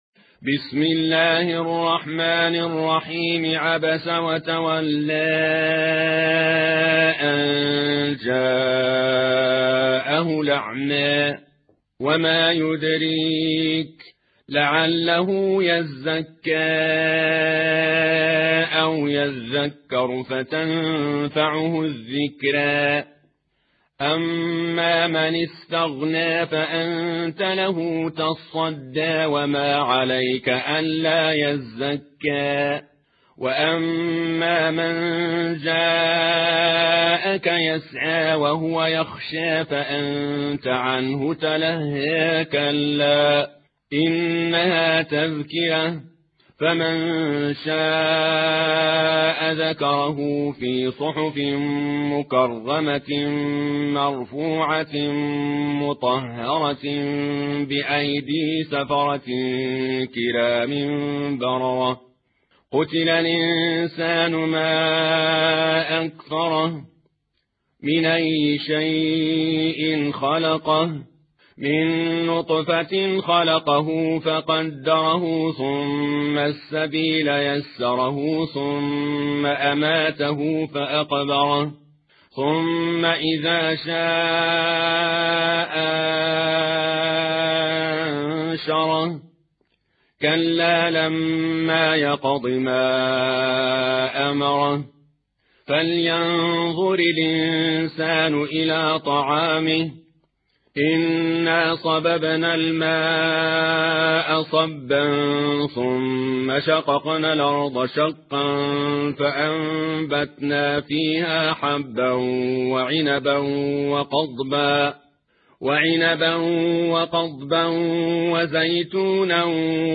Récitation